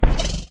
hit3.ogg